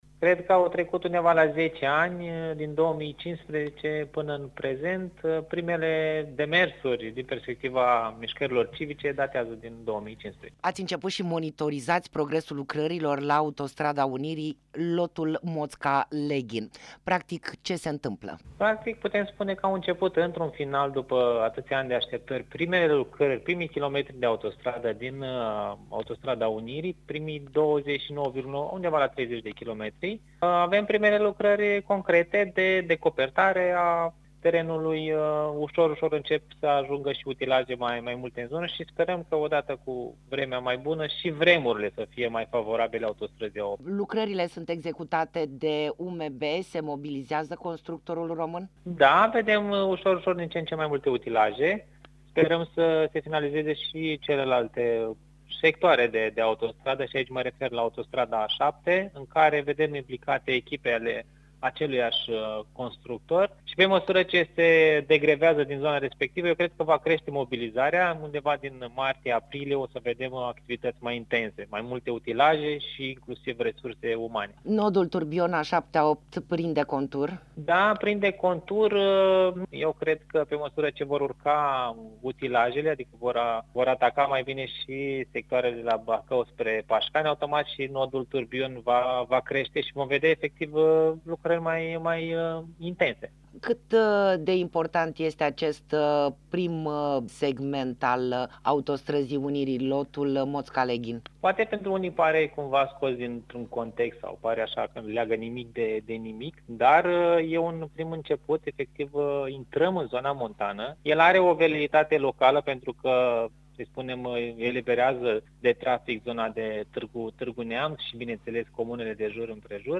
Interviu-activist-autostrada-unirii.mp3